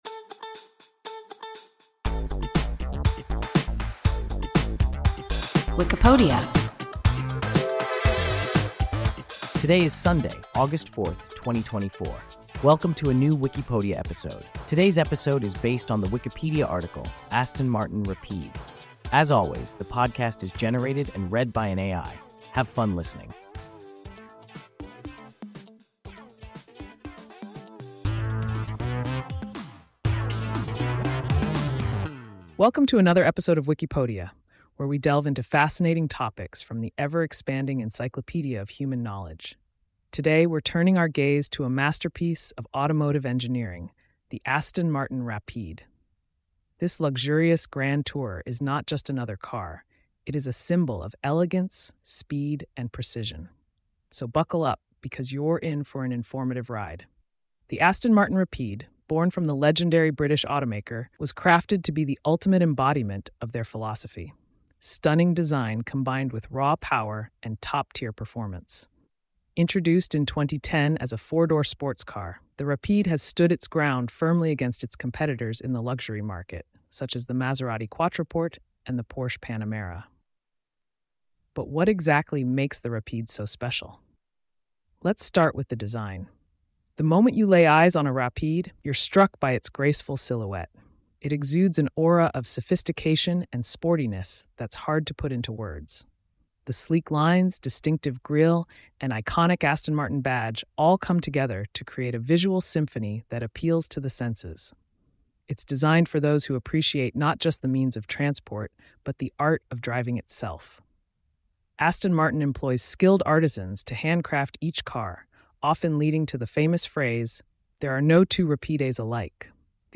Aston Martin Rapide – WIKIPODIA – ein KI Podcast